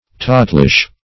Search Result for " tottlish" : The Collaborative International Dictionary of English v.0.48: Tottlish \Tot"tlish\ (t[o^]t"tl[i^]sh), a. Trembling or tottering, as if about to fall; unsteady.